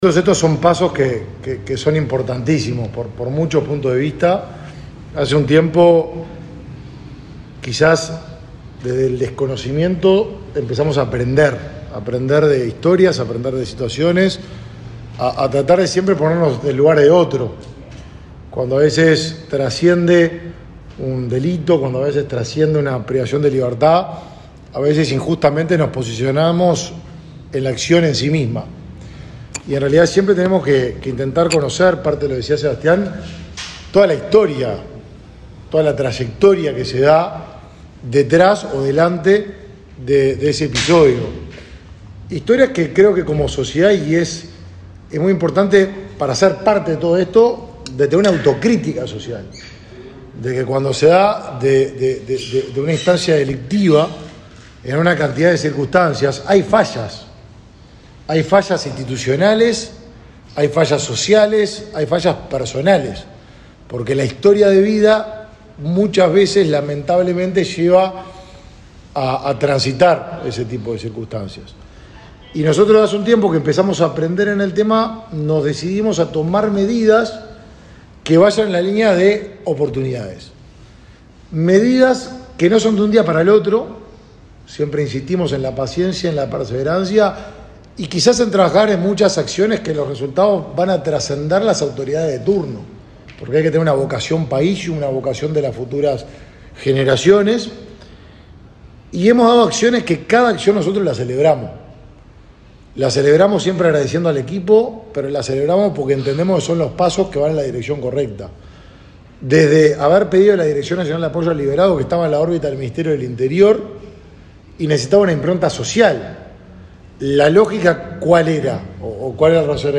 Palabras del ministro de Desarrollo Social, Martín Lema
Este martes 26, el ministro de Desarrollo Social, Martín Lema, participó del acto de instalación de un consultorio jurídico gratuito para personas que